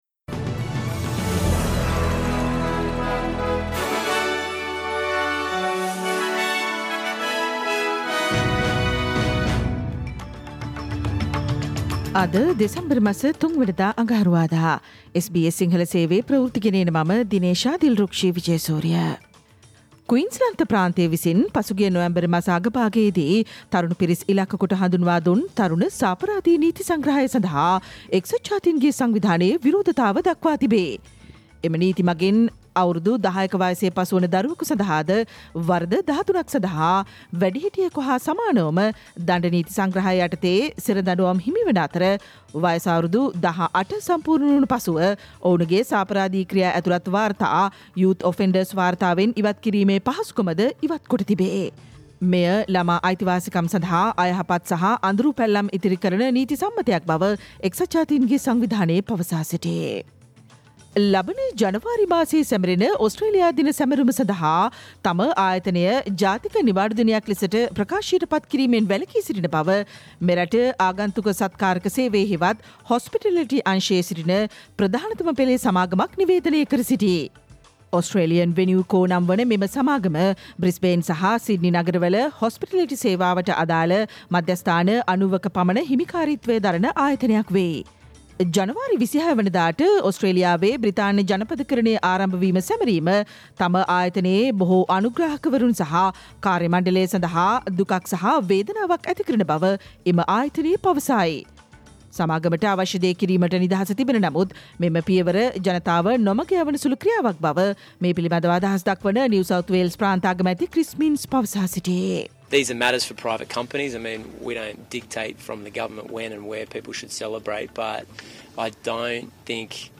Australian news in Sinhala, foreign and sports news in brief. Listen, Tuesday 03 December SBS Sinhala radio news flash